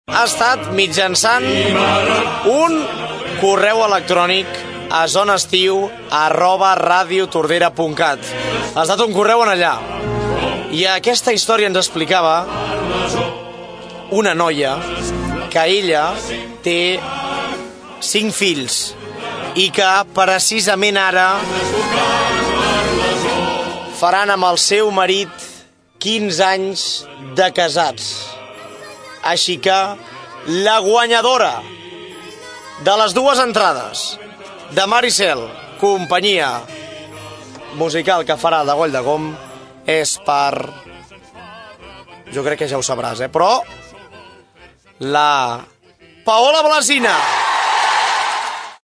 Aquest agost, el programa de Ràdio Tordera, Zona d’Estiu oferia aquestes entrades entre els oients. Ahir es va fer el sorteig entre les persones que havien participat en el concurs.